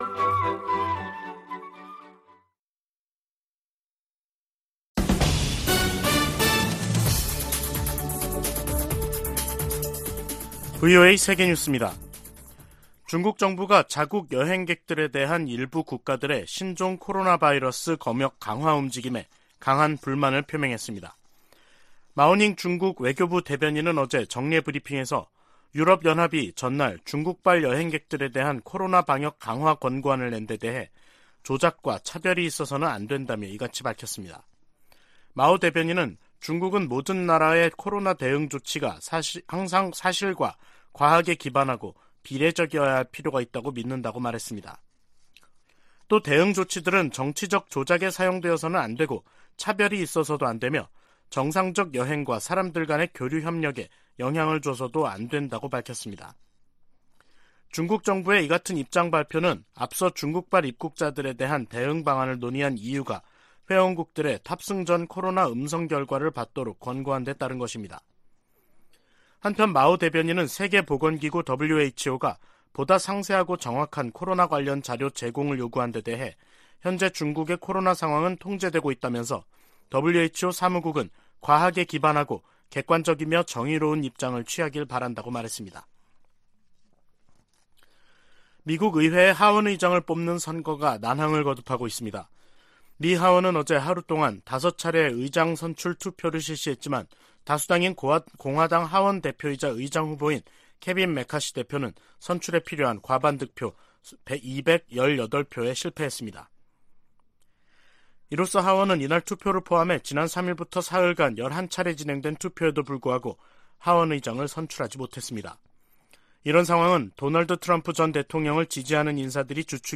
VOA 한국어 간판 뉴스 프로그램 '뉴스 투데이', 2023년 1월 6일 2부 방송입니다. 미국과 일본이 워싱턴에서 외교 국방장관 회담을 개최한다고 미 국무부가 발표했습니다. 미 국방부가 북한의 핵탄두 보유량 증대 방침에 대한 우려를 표시했습니다.